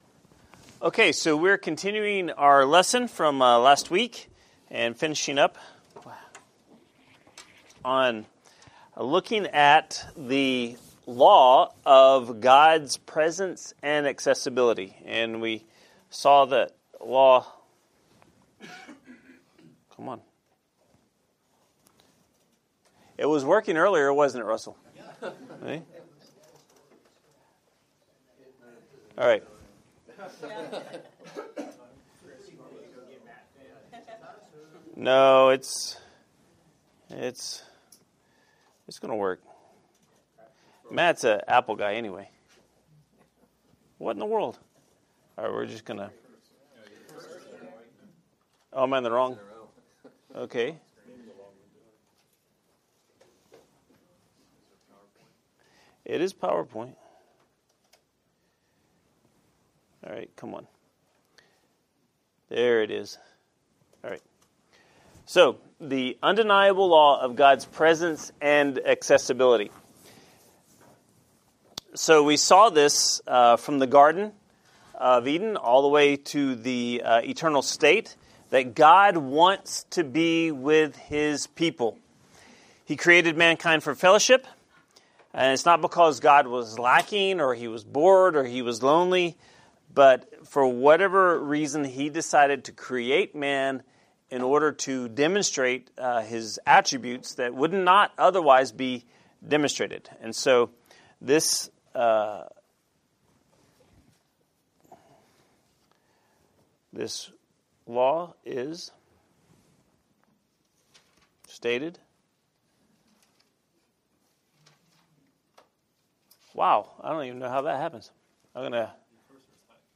Adult Bible Study